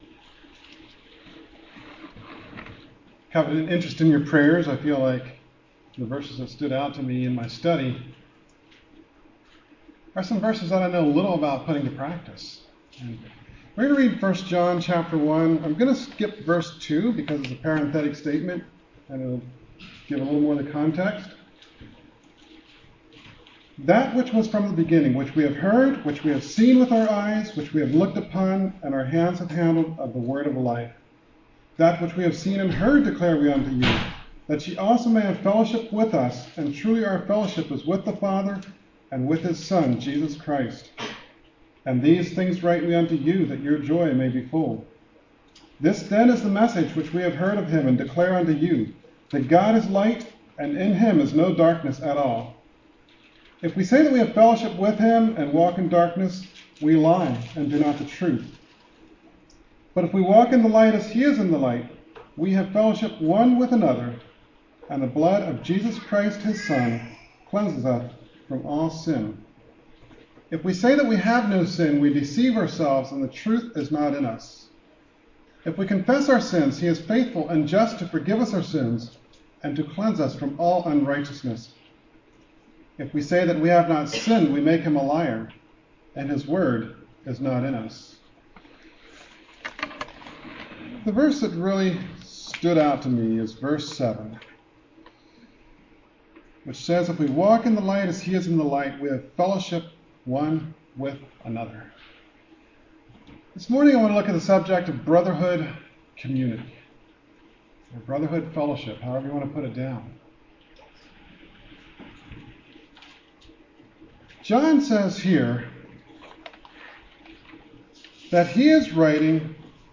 Sermons
Kirkwood | All Day Meetings 2024